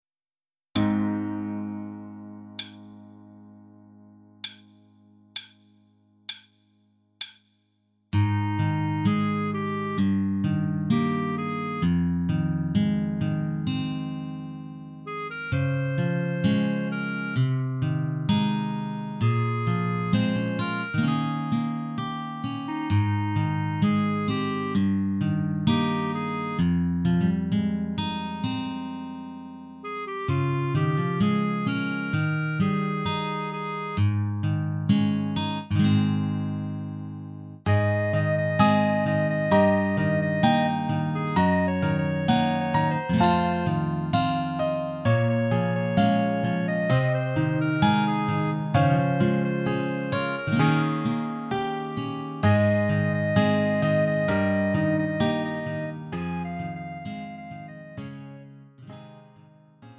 음정 원키 3:59
장르 구분 Lite MR